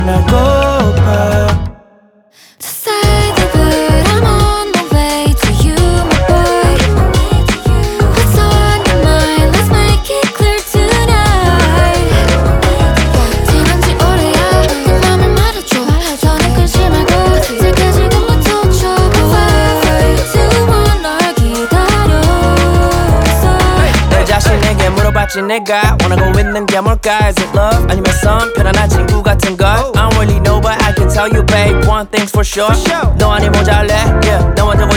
Hip-Hop Rap
Жанр: Хип-Хоп / Рэп